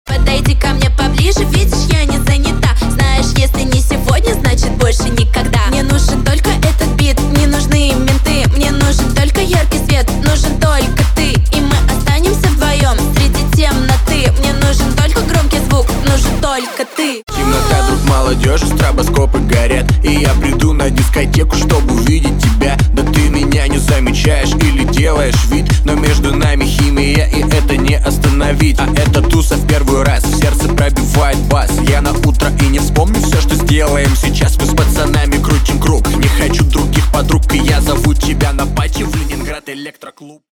дуэт
Hardstyle
рейв
цикличные